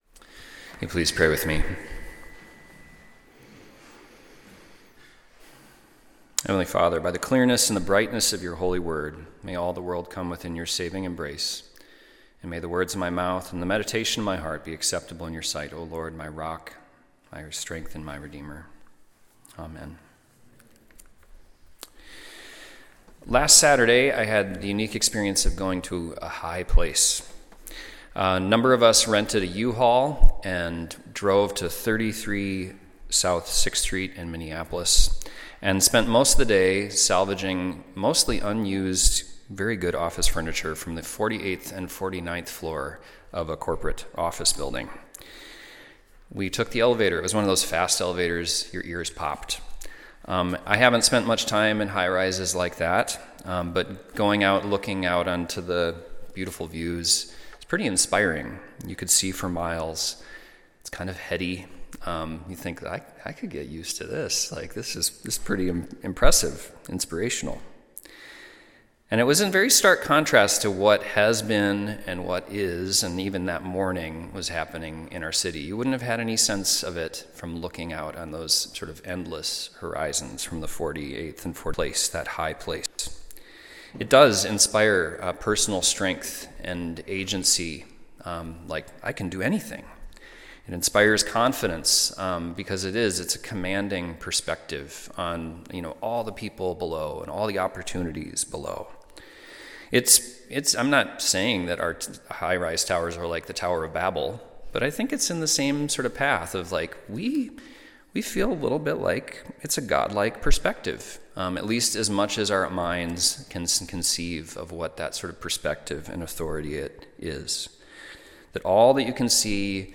Sunday Worship–February 1, 2026
Sermons